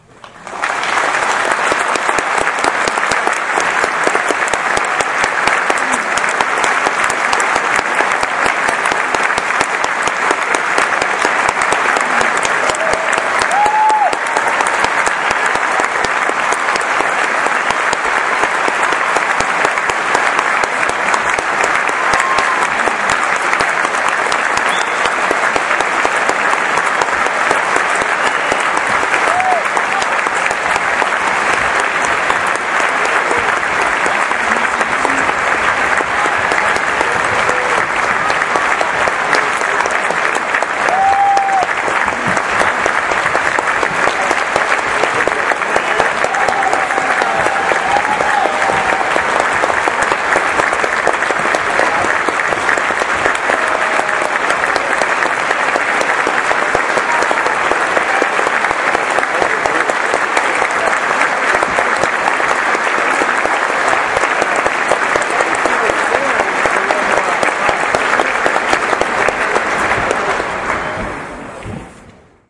Claps
描述：A short recording of a small crowd at a nursing home applauding a performance.
标签： applause clapping concert nursinghome smallroom
声道立体声